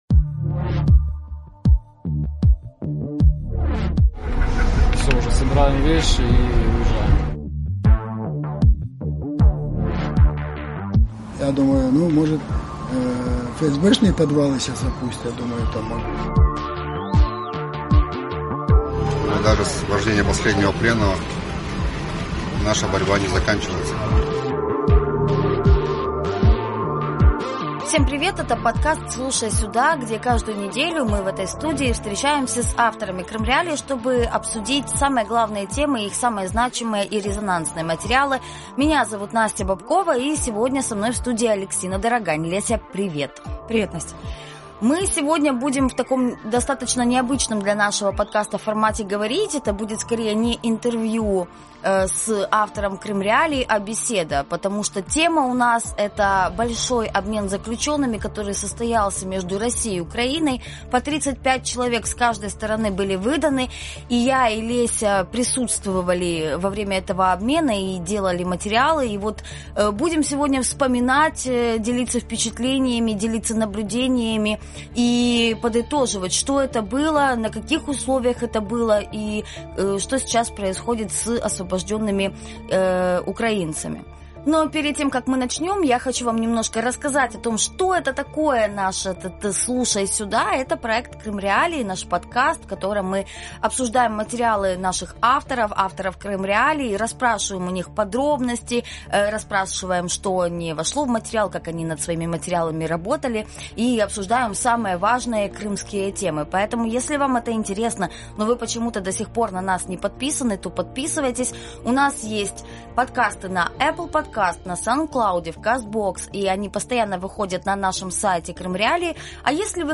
Это, пожалуй, самый эмоциональный выпуск подкаста «Слушай сюда»